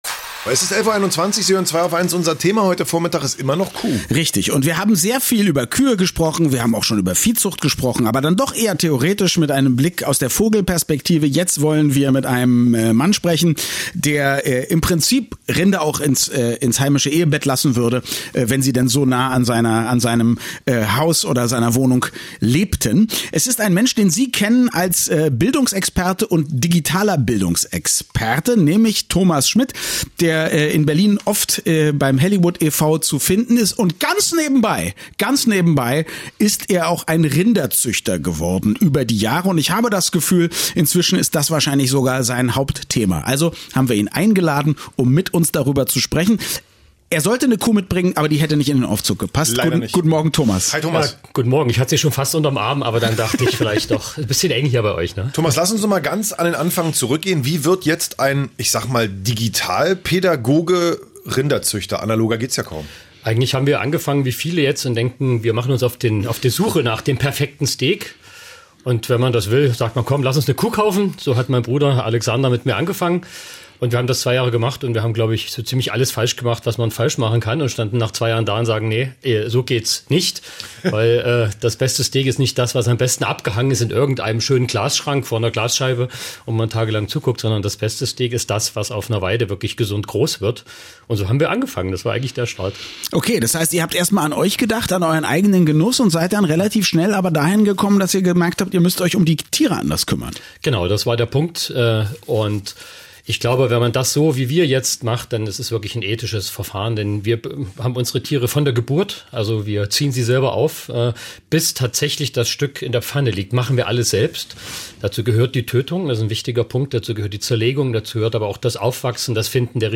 Zu Besuch bei Zwei auf eins von Radioeins
Interview_Radio1_2018.mp3